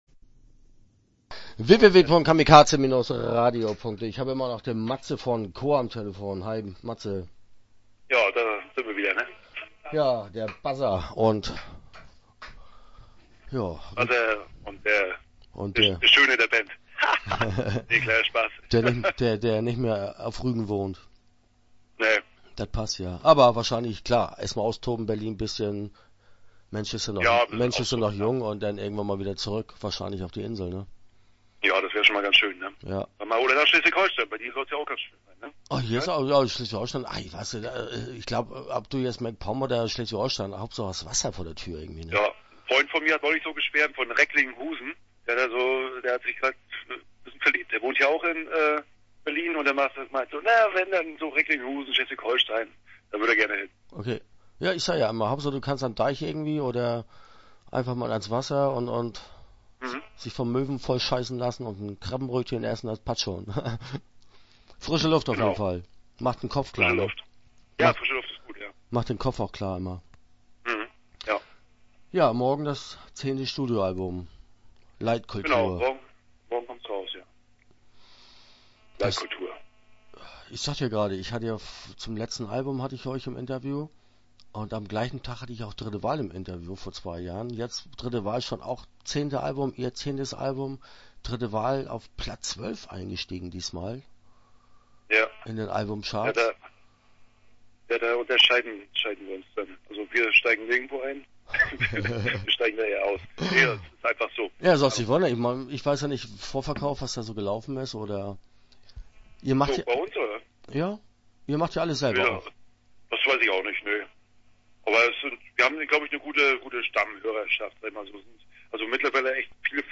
Interview Teil 1 (10:43)